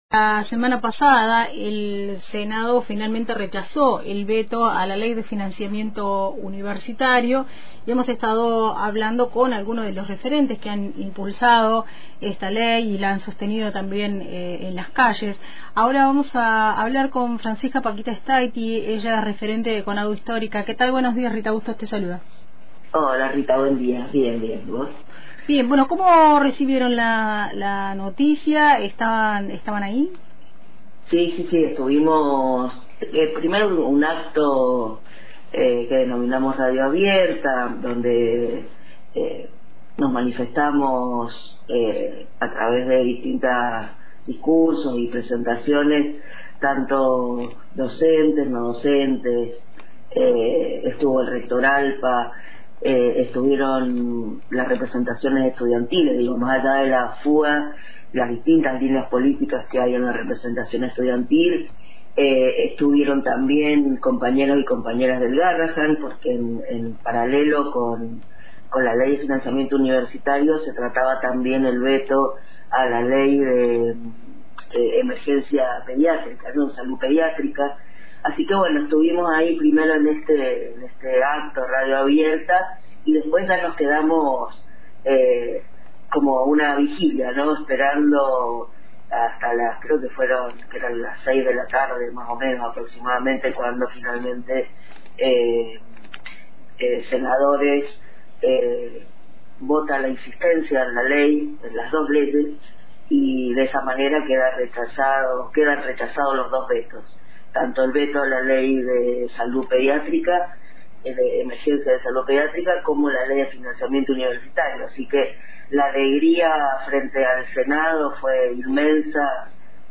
En diálogo con Radio Antena Libre